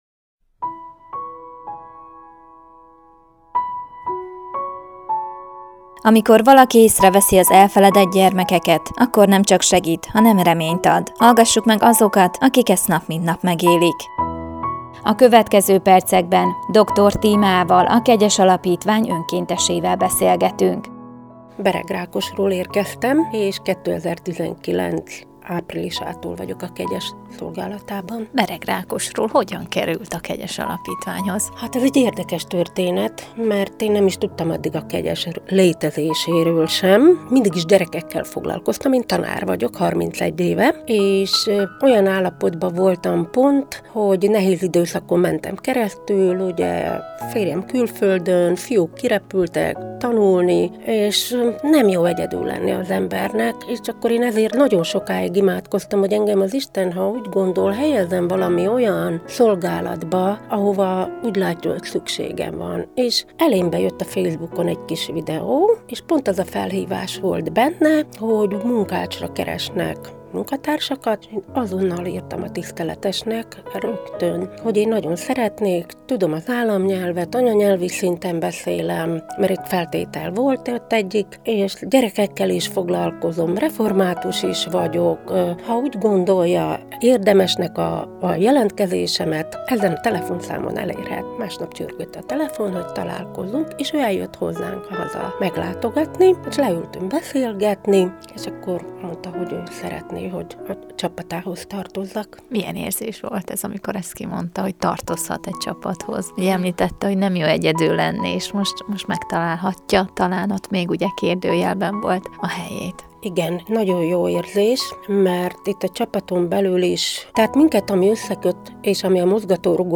Műsorok/Riportok
A rádióban elhangzott riportok, műsorok itt visszahallgathatóak.